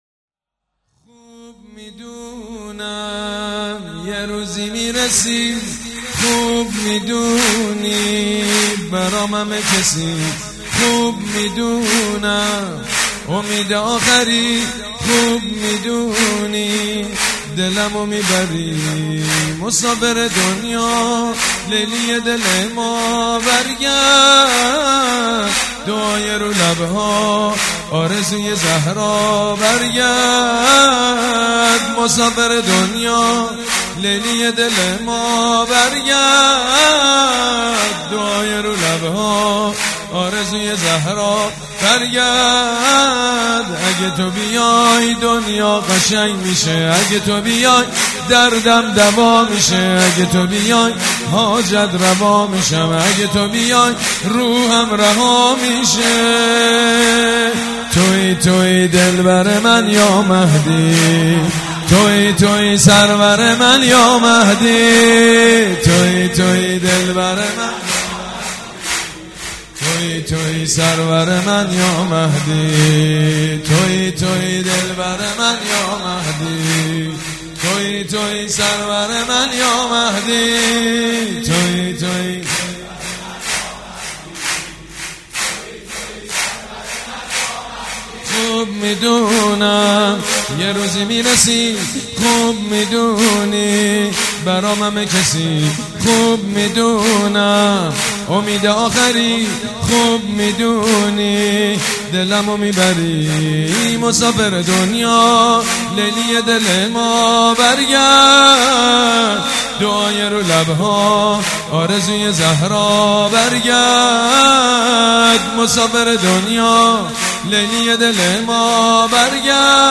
سرود
جشن نیمه شعبان